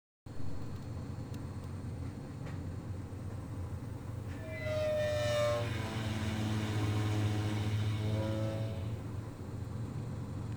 [Thermique] Bruit anormal Chaudière chaffoteaux & maury
J'ai une chaudière Chaffoteaux et Maury Nectra-Top 2.23FF. Je viens de purger certains radiateurs et j'ai remis de la pression avant de remettre en service le chauffage. Mais maintenant lorsque les bruleurs s'enclenchent j'entends un bruit anormal (ci-dessous) dans le compartiment de chauffe.
Ce bruit se produit seulement lors de la demande Chauffage.
Bonjour à vous deux, pour ma part je penche sur une dépression trop importante qui génére ce bruit caractéristique que j'apparente à un bruit d'éléphant mais bon chacun y verra l'association qui lui plait, peu importe c'est le bruit de l'air aspiré que l'on entend, la chaudière est équipée de toutes les sécurités utiles et si rien ne va pas bien c'est que c'est utilisable en l'état sauf si la chaudière est dans l'habitation car là il y a gêne du fait du bruit